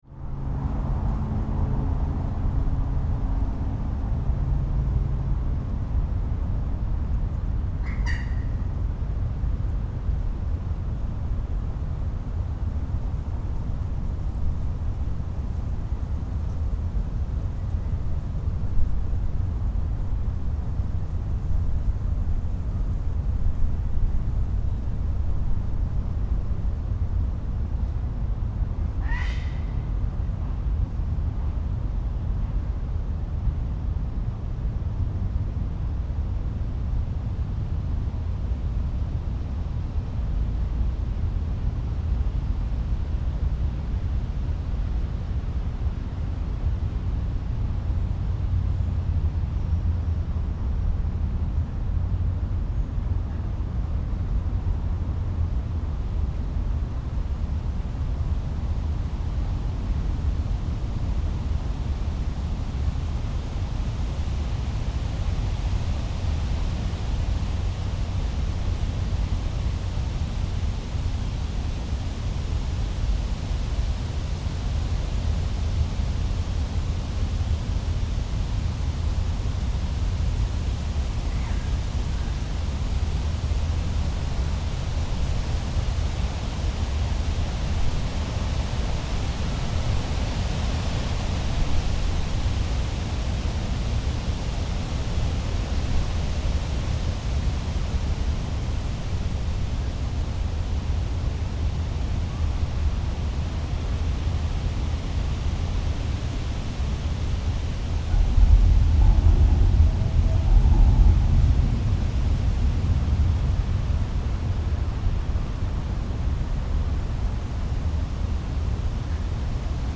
For the recording I used a directional microphone, a contact microphone, an ultrasonic detector (bats) and a binaural microphone.